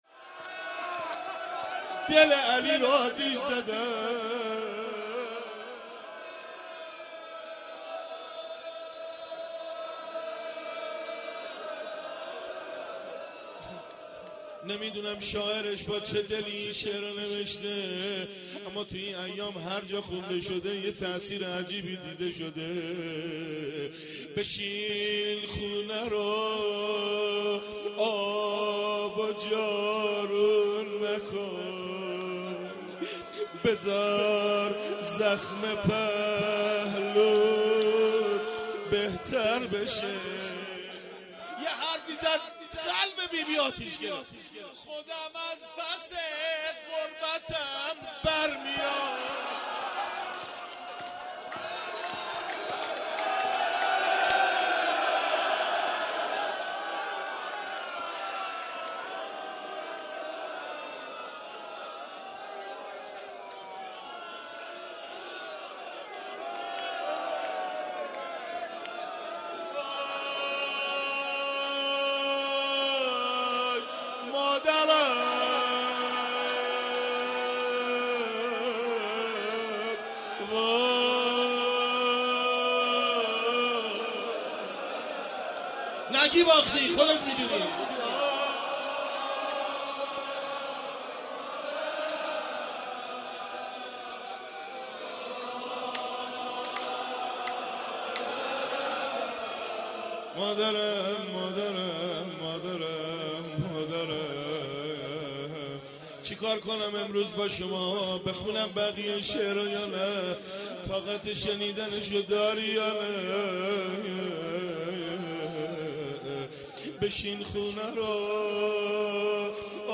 مراسم عزاداری ظهر شهادت فاطمیه اول ۱۳۹۳